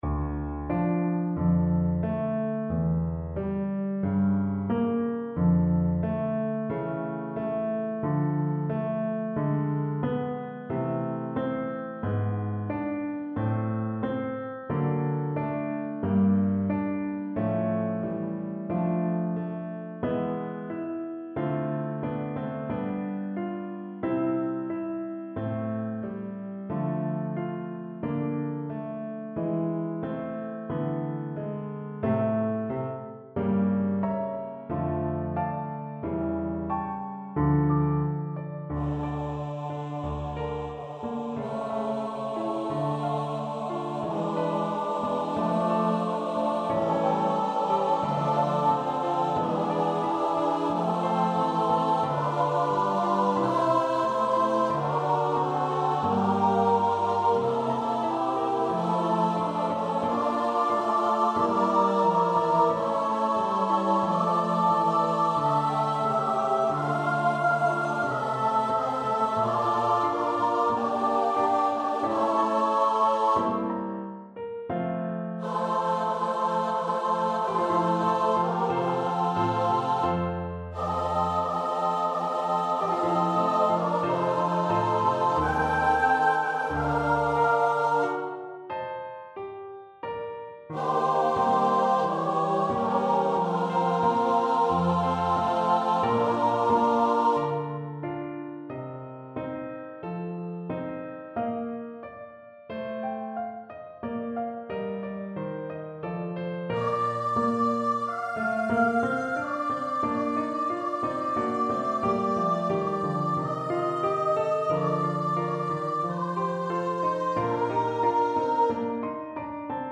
Choir  (View more Intermediate Choir Music)
Classical (View more Classical Choir Music)